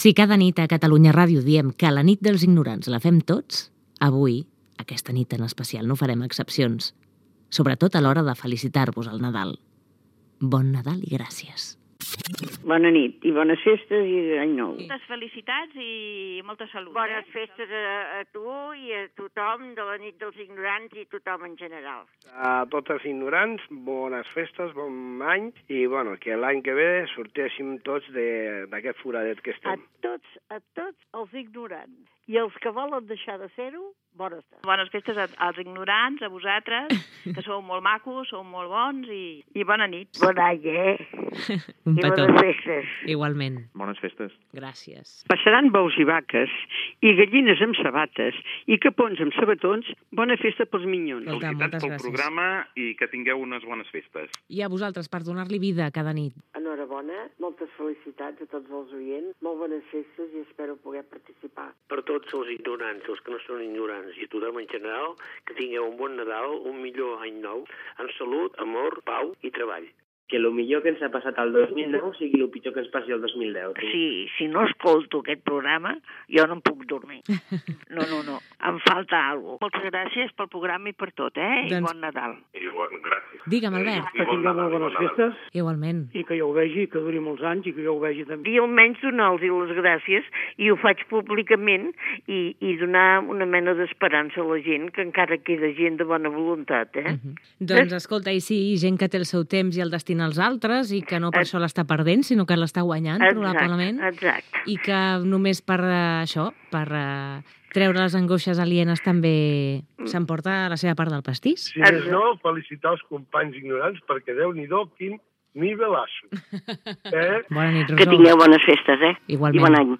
Els oïdors desitgen bon Nadal i bon any 2010, identificació del programa, sintonia i presentació.